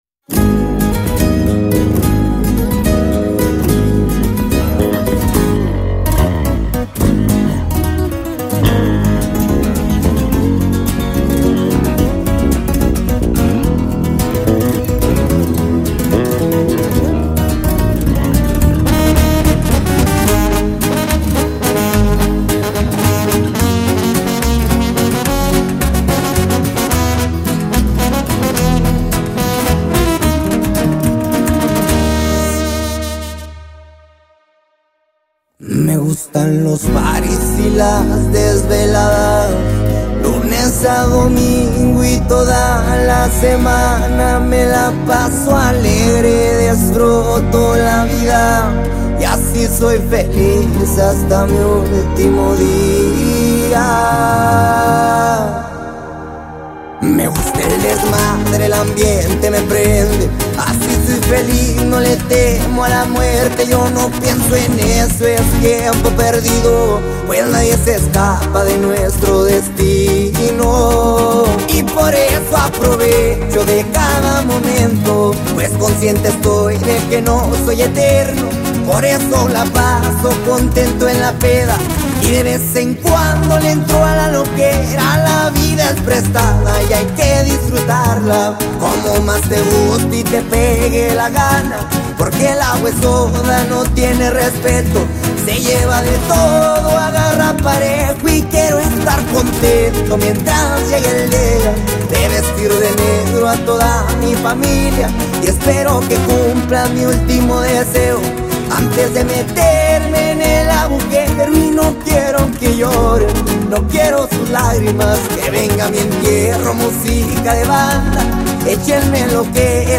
It keeps a steady rhythm that feels just right.